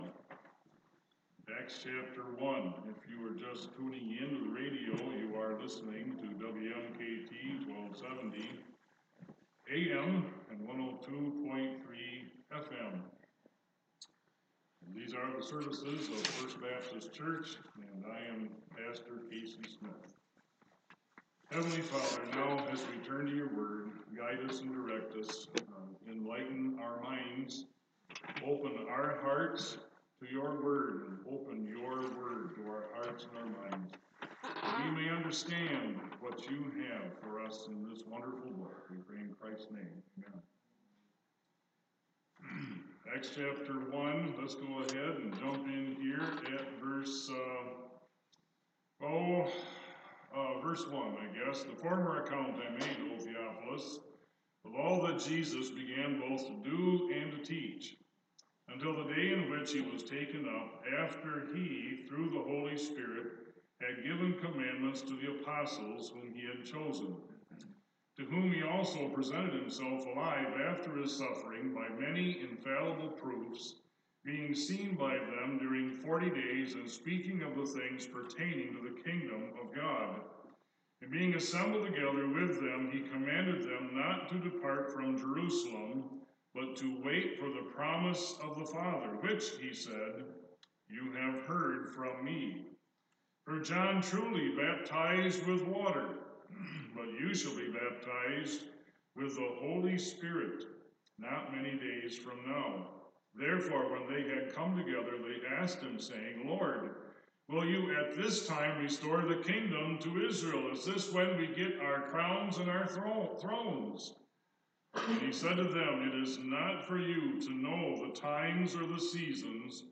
Sunday Morning Message – June 16, 2019 | First Baptist Church of Petoskey Sunday Morning Bible Teaching